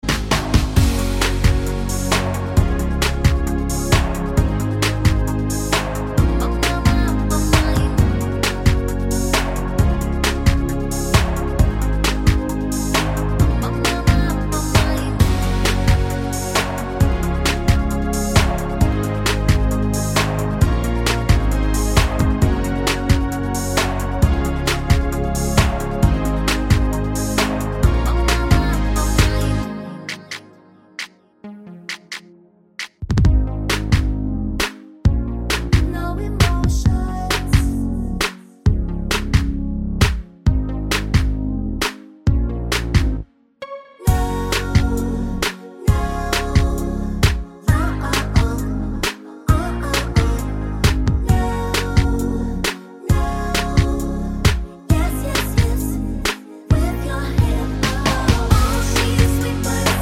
With Intro Pop